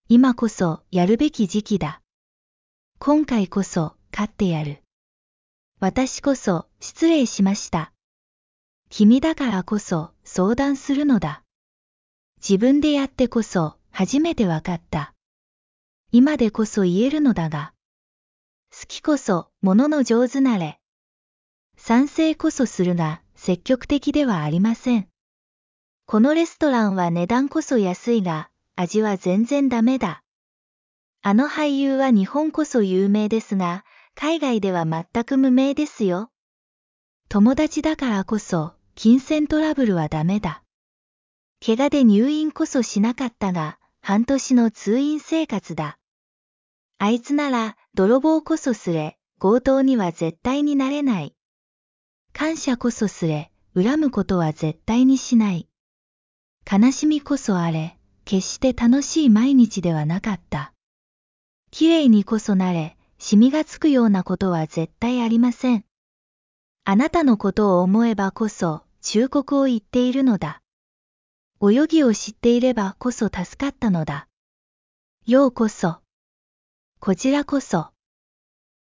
免費學日文格助詞助詞 「こそ」的完整講解
日文助詞こそ的完整用法.mp3